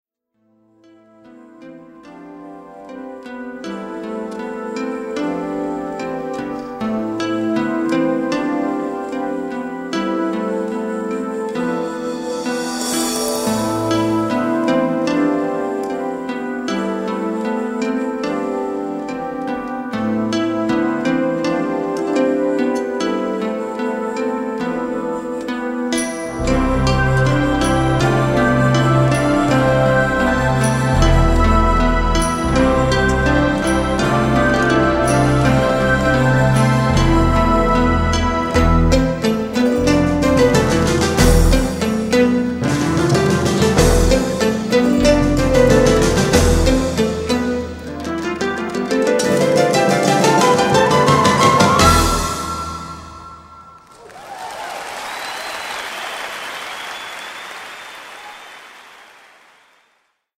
Live recordings from: